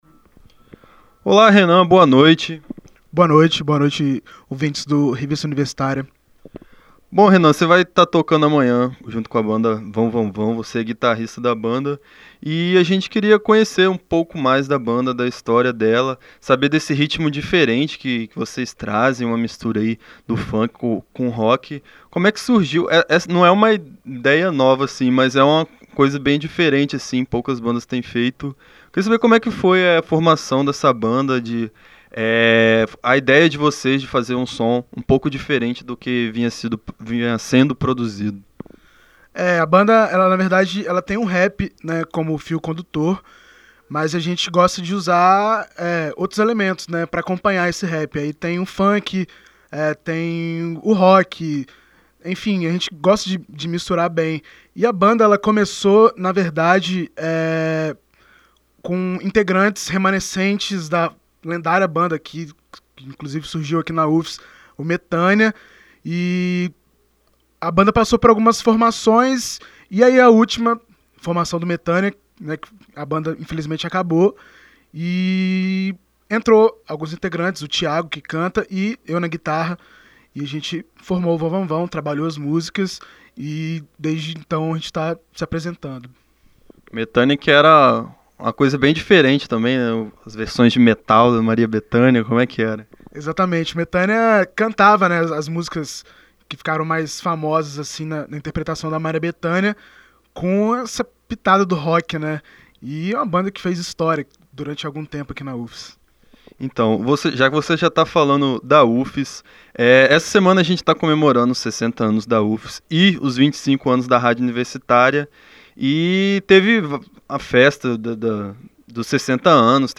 Entrevista Vão Vão Vão